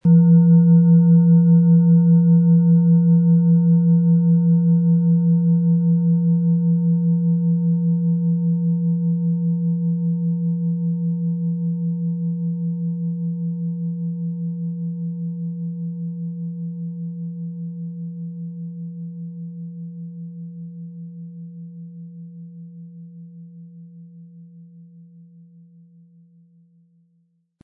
Planetenton
Handgearbeitete tibetische Schale mit dem Planetenton Mars.
Um den Original-Klang genau dieser Schale zu hören, lassen Sie bitte den hinterlegten Sound abspielen.
Der Schlegel lässt die Schale harmonisch und angenehm tönen.
MaterialBronze